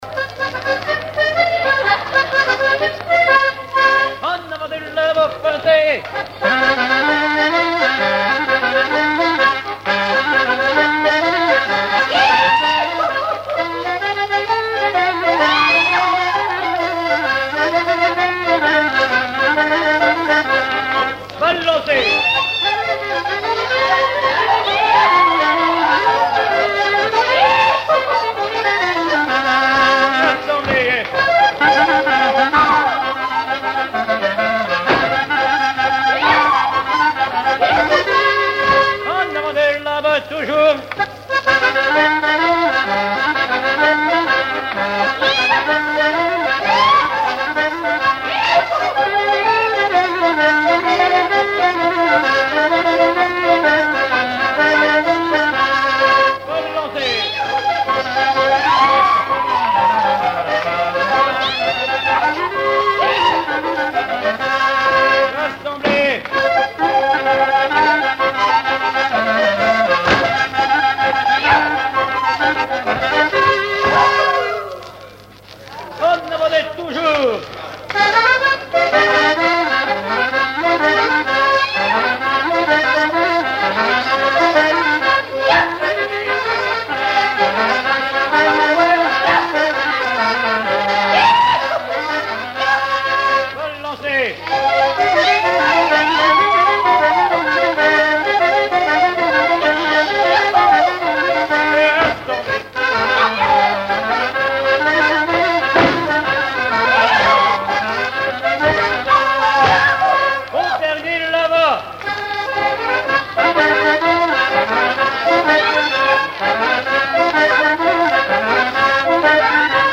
Chants brefs - A danser
danse : branle : avant-deux
Pièce musicale inédite